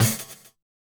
snare02.wav